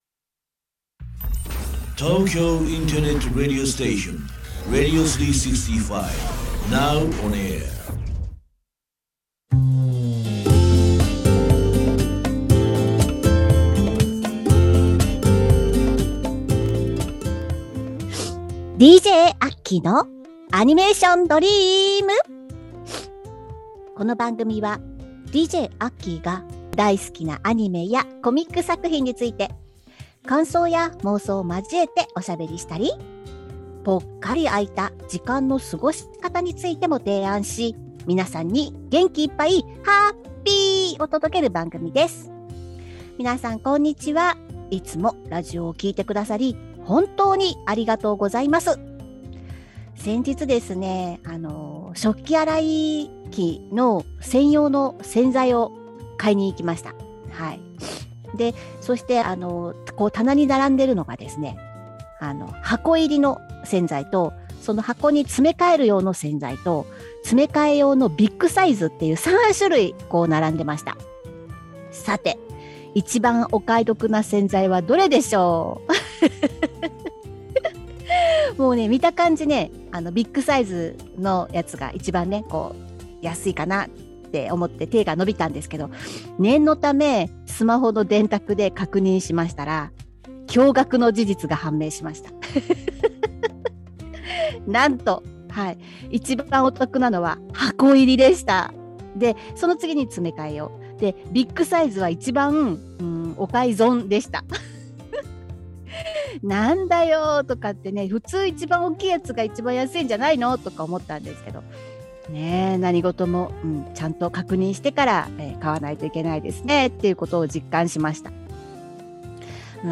今年も始まった花粉症ですが、滝のように鼻水が落ちています。お聞き苦しいことと思いますが、どうか、ご勘弁願います。